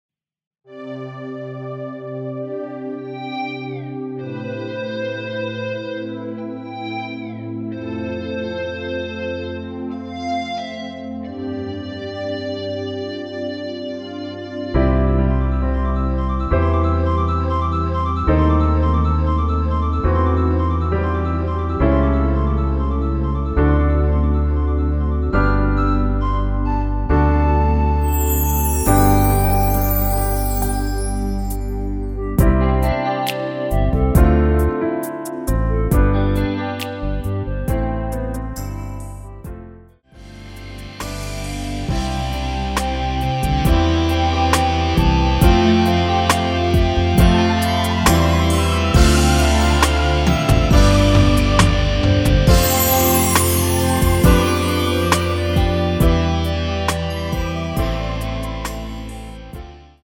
멜로디 MR이라고 합니다.
앞부분30초, 뒷부분30초씩 편집해서 올려 드리고 있습니다.
중간에 음이 끈어지고 다시 나오는 이유는